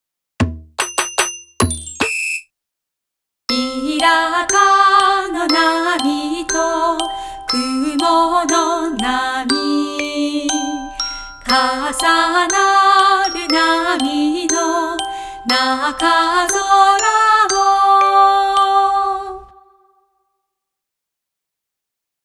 童謡を中心にわらべ歌、唱歌、民謡を収録。